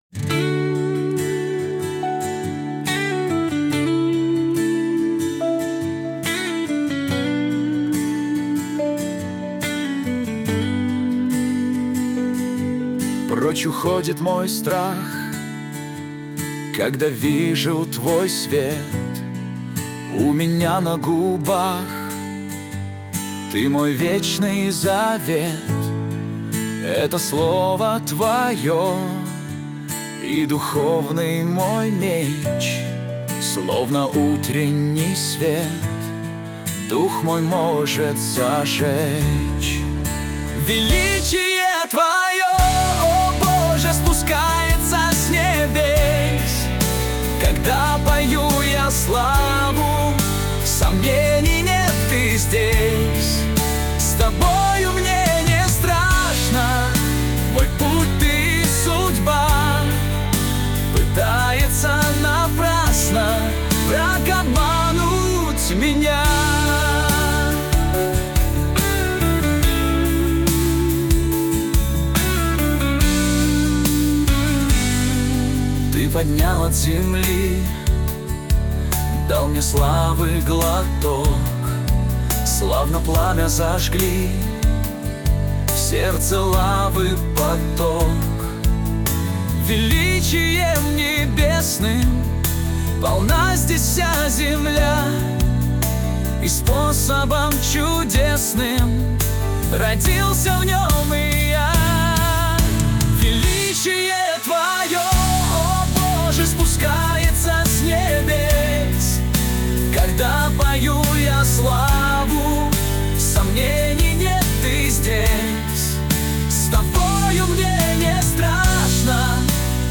песня ai
308 просмотров 1810 прослушиваний 105 скачиваний BPM: 70